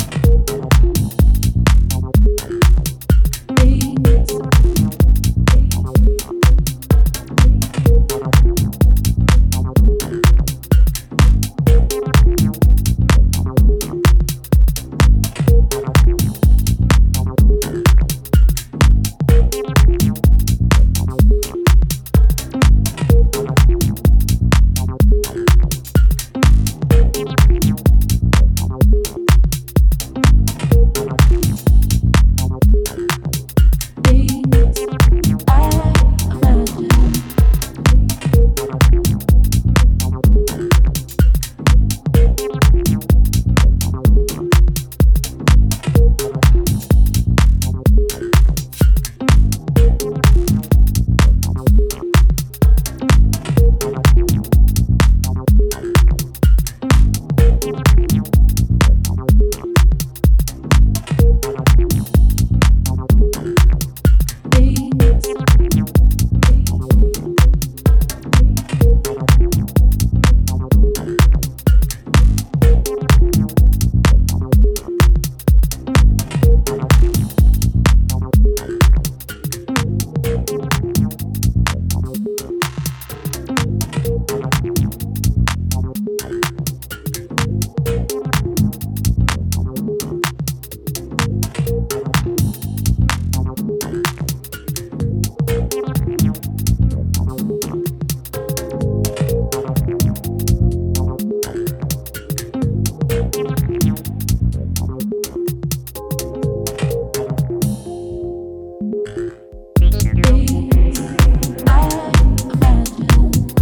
Recorded in Berlin
sub basslines, acid synths, and hypnotic chords